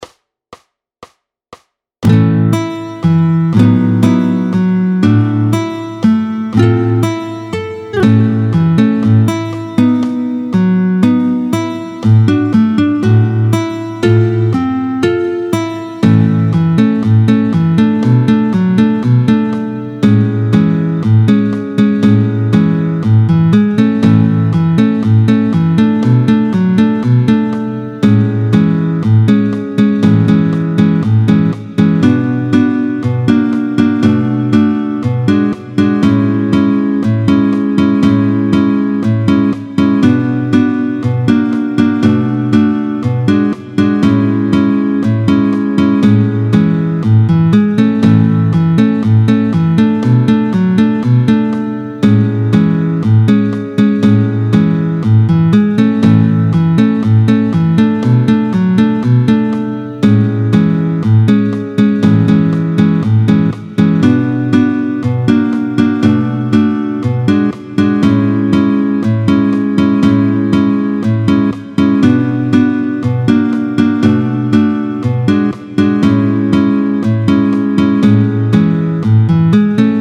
guitare démo